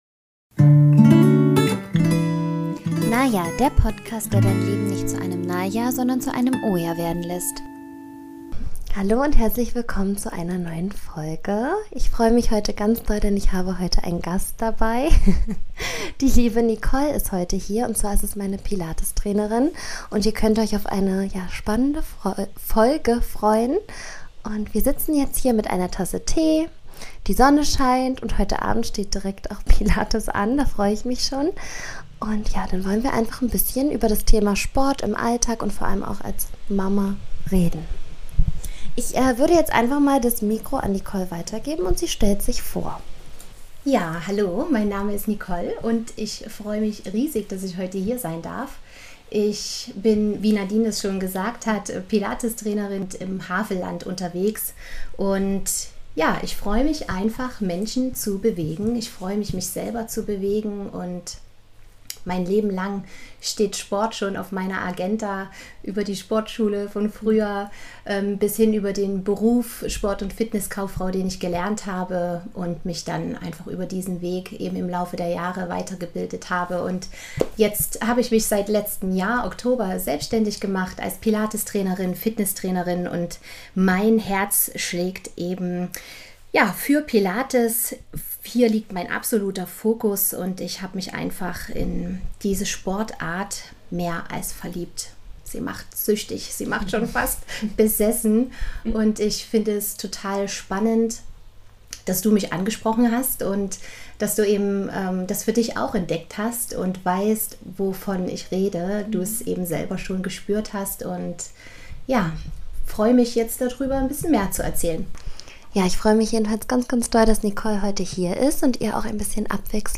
In der heutigen Folge habe ich einen Gast.